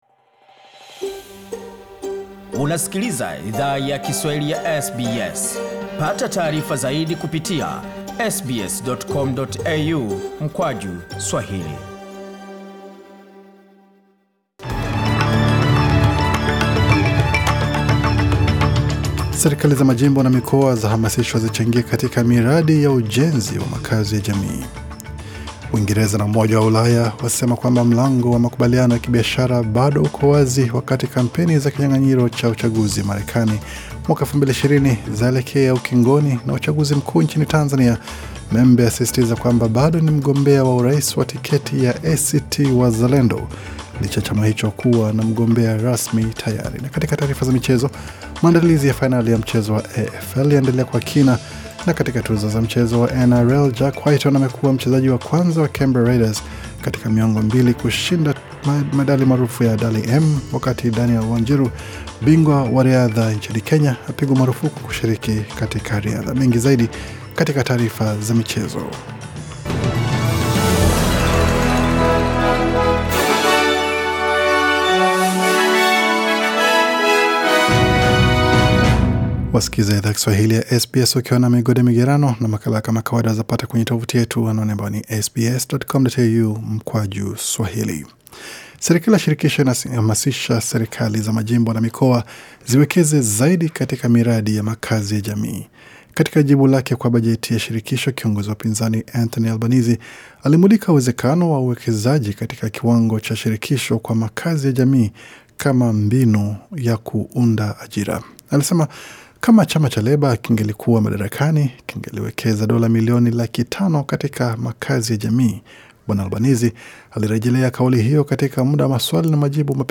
Taarifa ya habari 20 Oktoba 2020